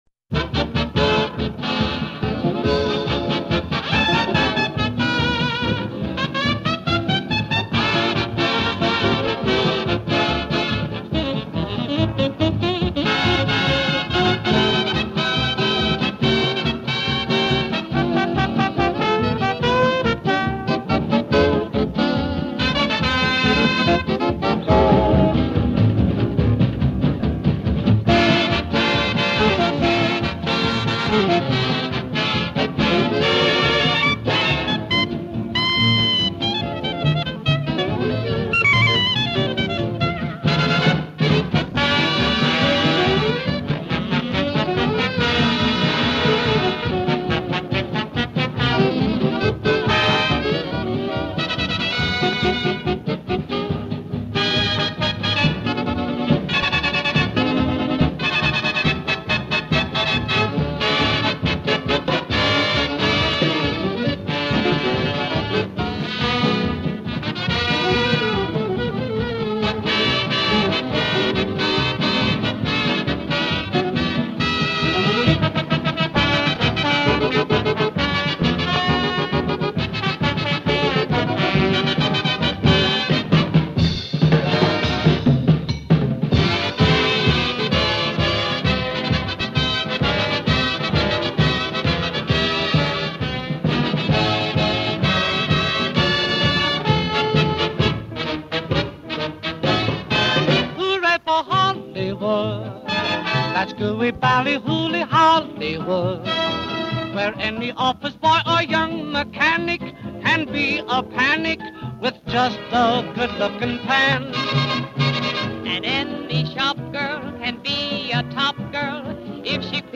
I like the jazzy, swingy thing that the piece has going on.